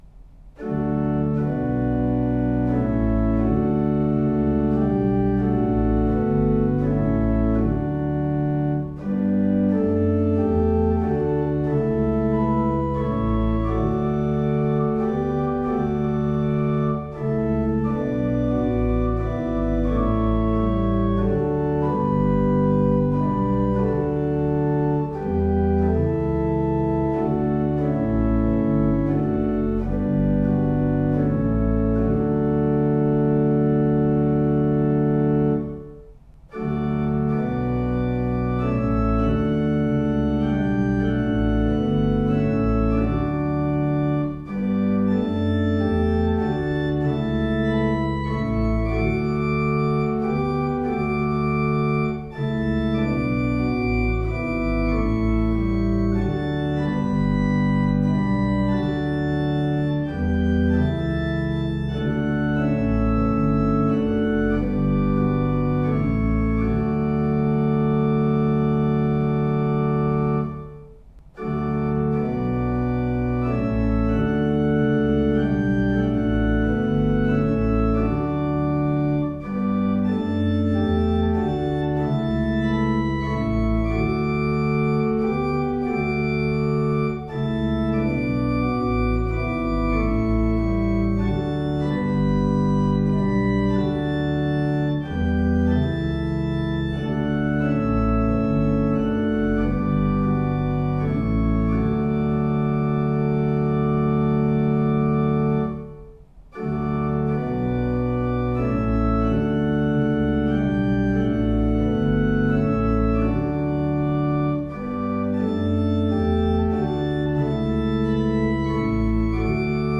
Audio Recordings (Organ)
WS702-midquality-mono.mp3